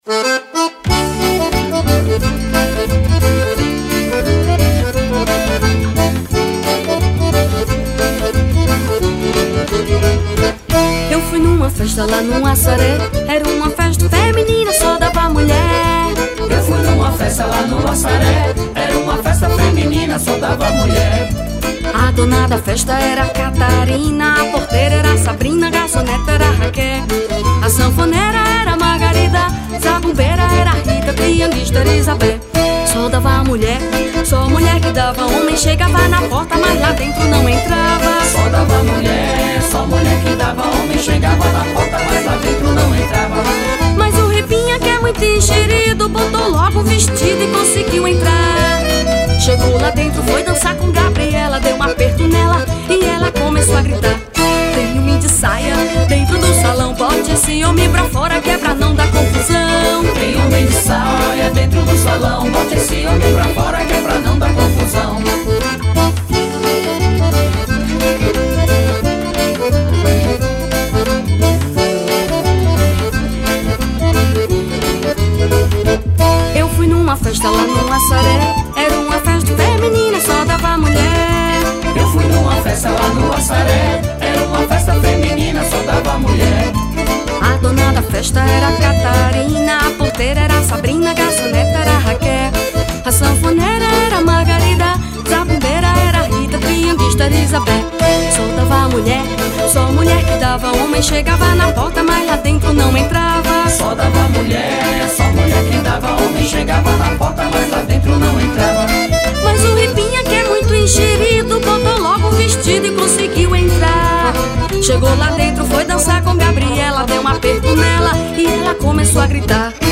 • Acordeom
• Violino
• Zabumba
• Triângulo e percussão
• Baixo e cavaquinho
• Backing Vocal